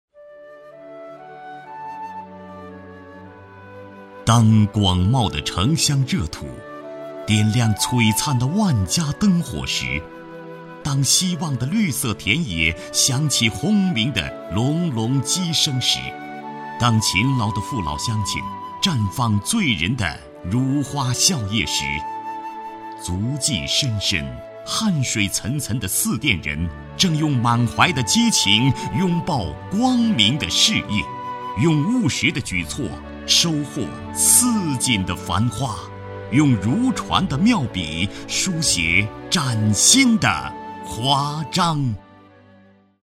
47男-学校宣传片
擅长：专题片 广告
特点：大气浑厚 稳重磁性 激情力度 成熟厚重
风格:浑厚配音